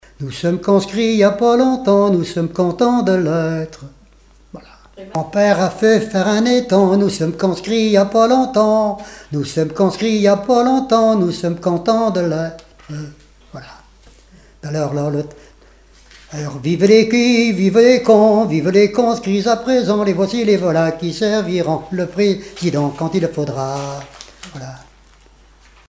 gestuel : à marcher
Genre laisse
Témoignages et chansons
Pièce musicale inédite